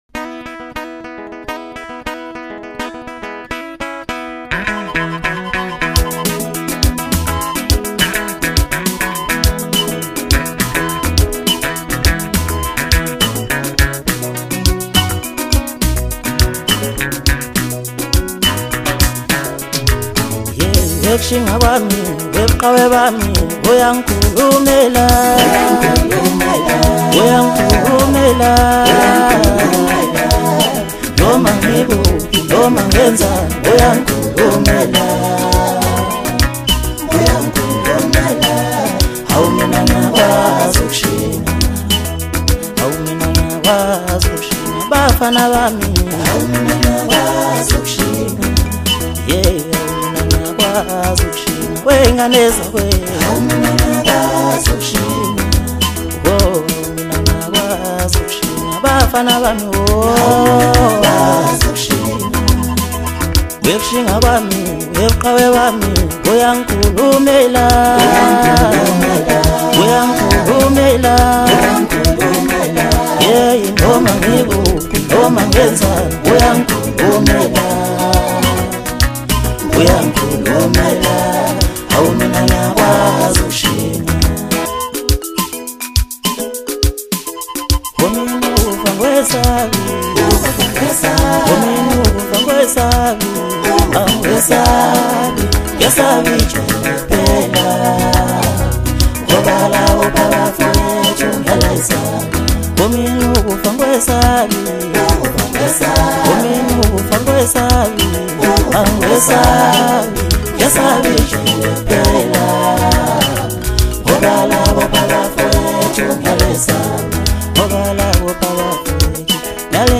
Home » DJ Mix » Hip Hop » Maskandi